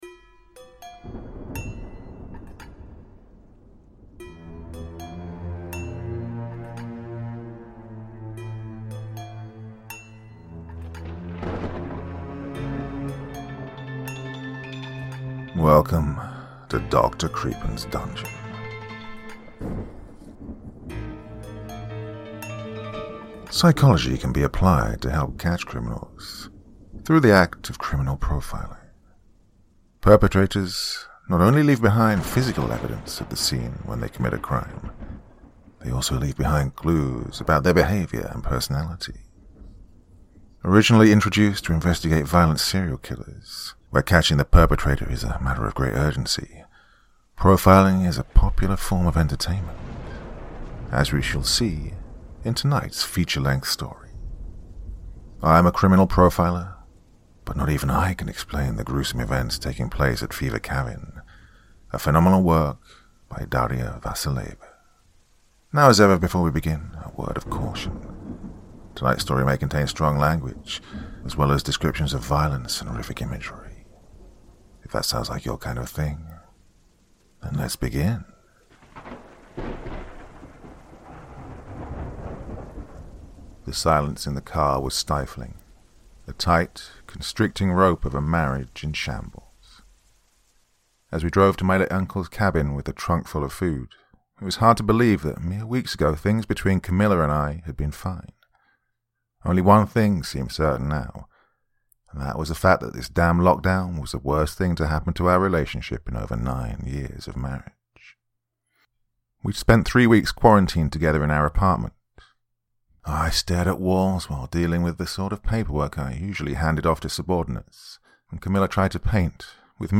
Today’s phenomenal feature-length story is ‘I'm a criminal profiler, but not even I can’t explain the gruesome events taking place at Fever Cabin’, a wonderful work by Daria Vasileva, kindly shared with me via NoSleep so I could read it here for you all with the author’s express permission.